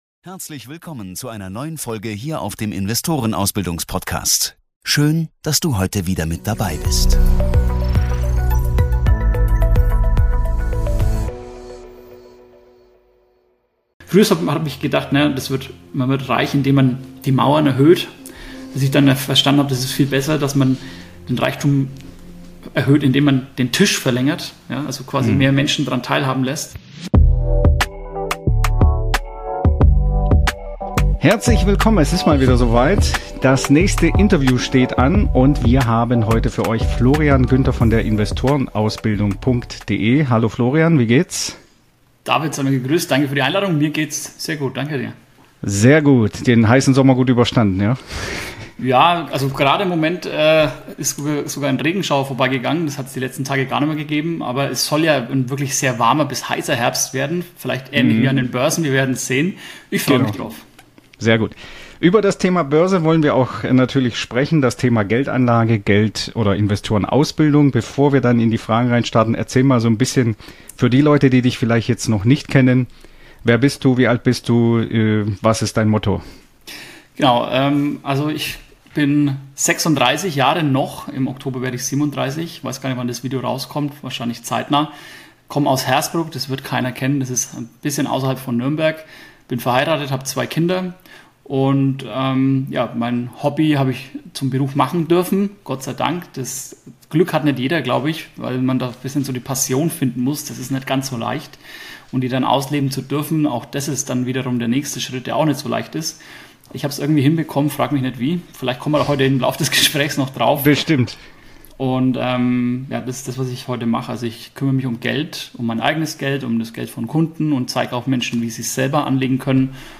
Ein Ex-Banker packt aus!